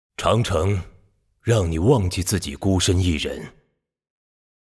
大厅语音